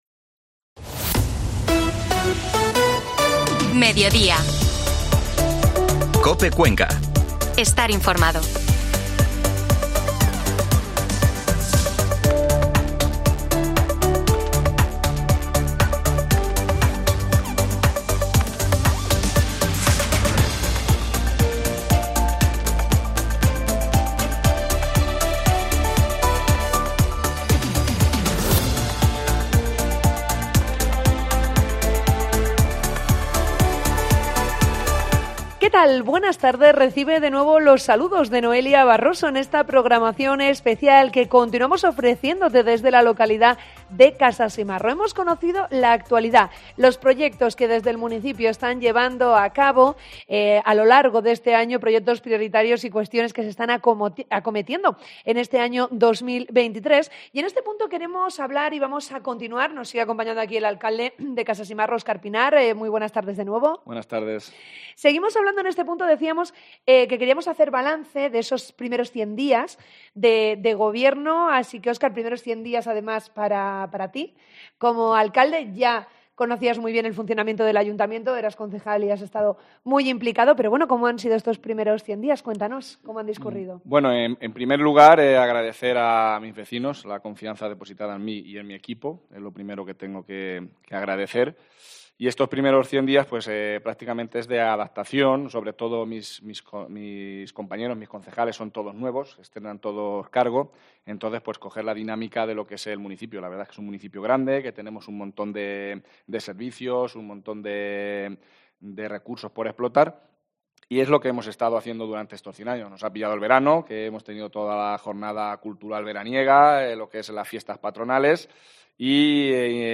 AUDIO: Escucha el programa especial de COPE Cuenca desde la localidad de Casasimarro